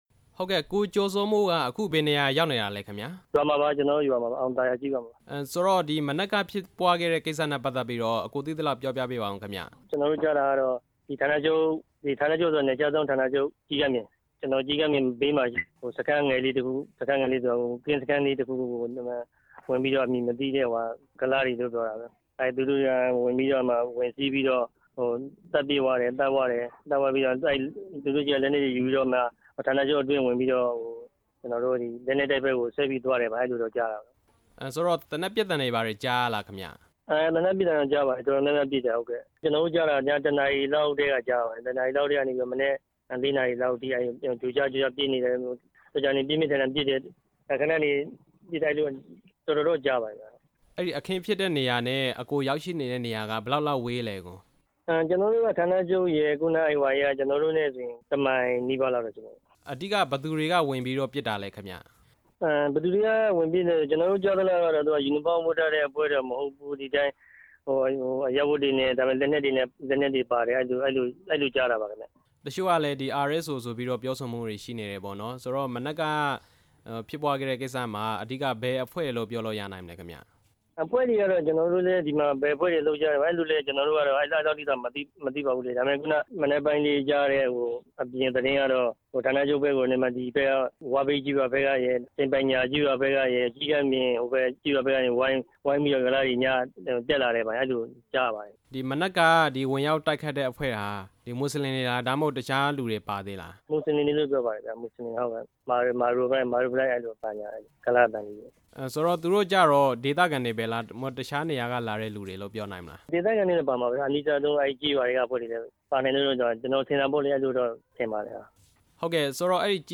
နယ်ခြားစောင့်ရဲကွပ်ကဲမှုဌာန ချုပ် တိုက်ခိုက်ခံရမှု မေးမြန်းချက်